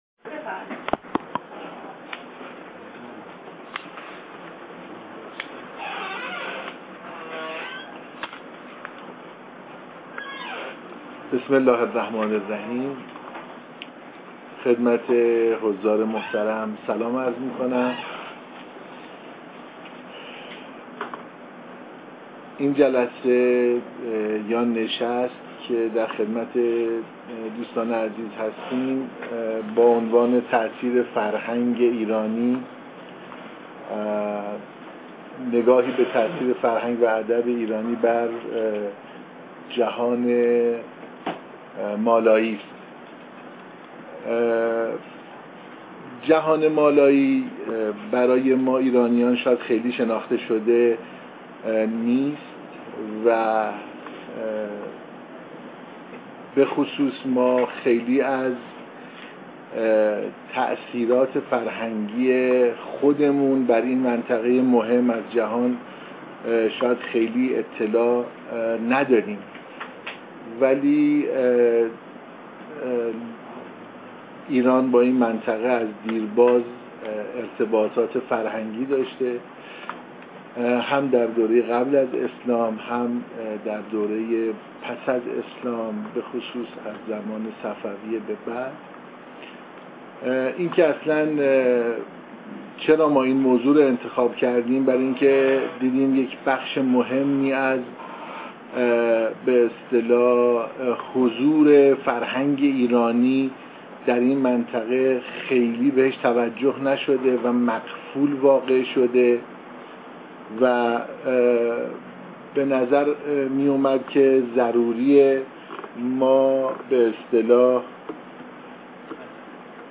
پژوهشکده زبان و ادبیات برگزار می کند: